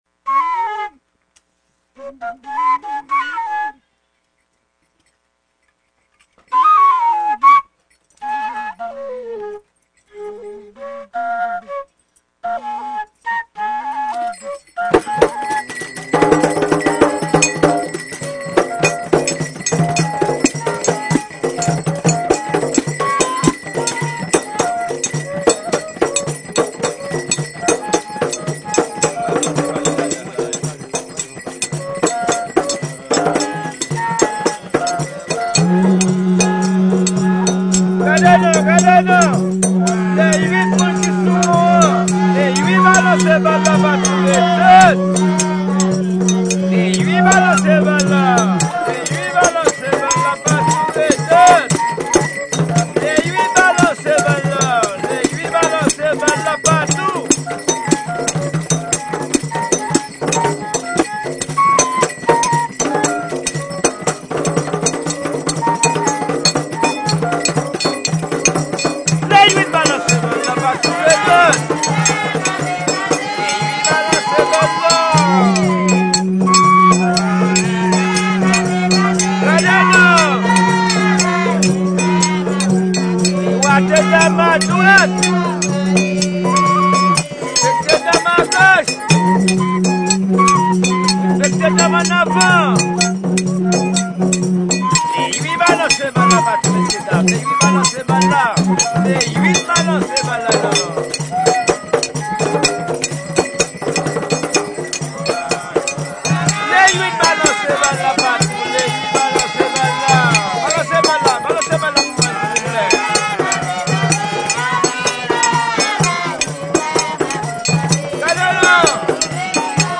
2022 CONTRE DANSE (VIEUX GRIS) FLOKLORE HAITIEN audio closed https